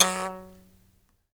Index of /90_sSampleCDs/Roland LCDP11 Africa VOL-1/PLK_Buzz Kalimba/PLK_HiBz Kalimba